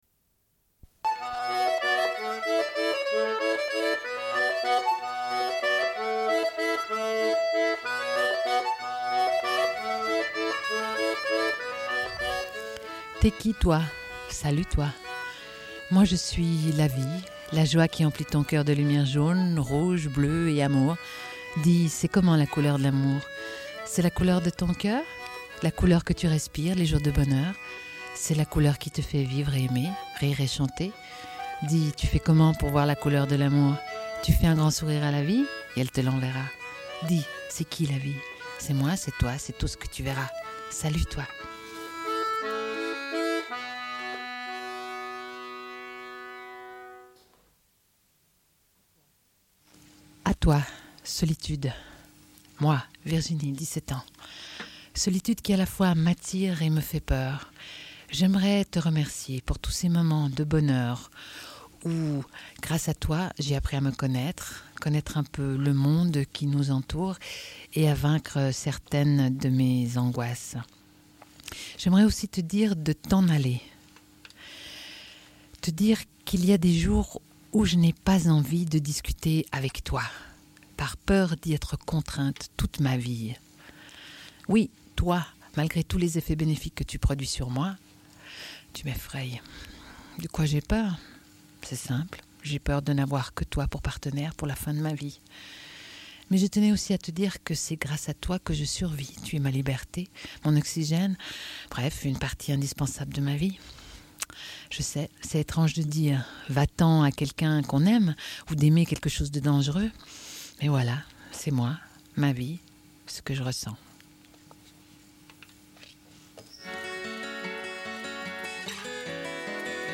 Suite de l'émission : au sujet du livre C'est aussi ma vie après tout ! : des jeunes (d)écrivent leur monde : témoignages d'une centaine de jeunes, Editions Ouverture, 1997. Lecture d'extraits.